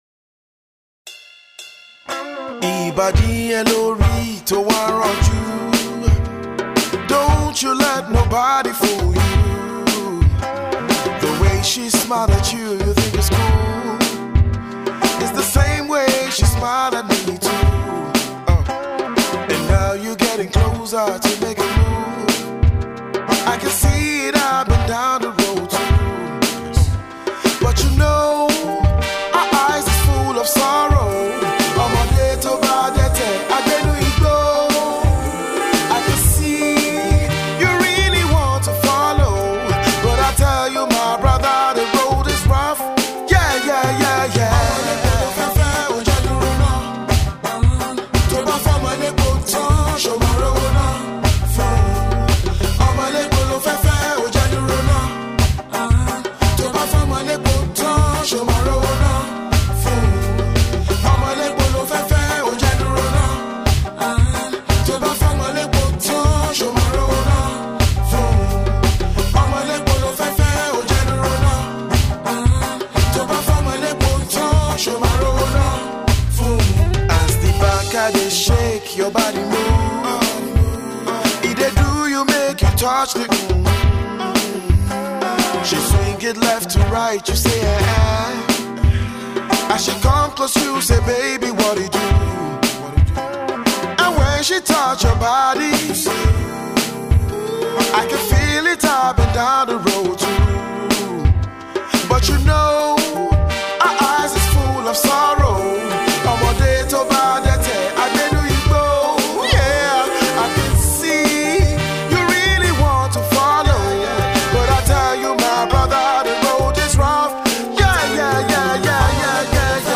soothing vocals